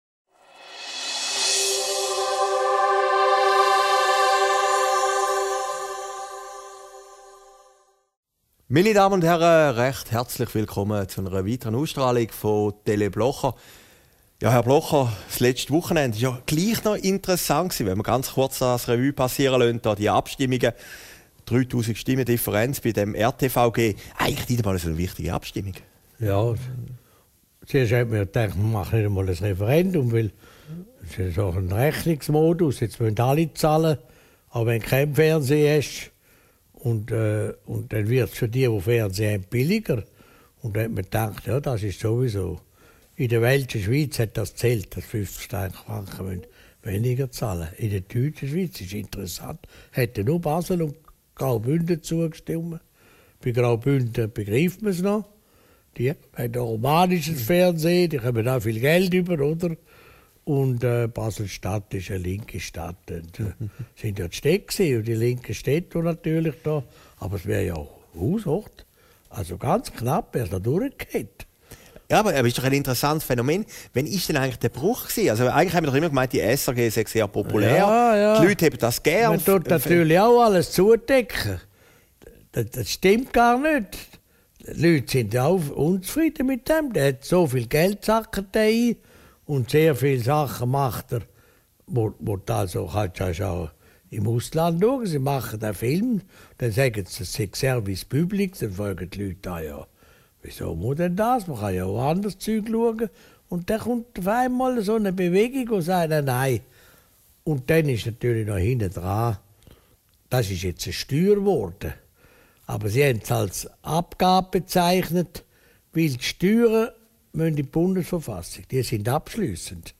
Aufgezeichnet in Herrliberg, 18. Juni 2015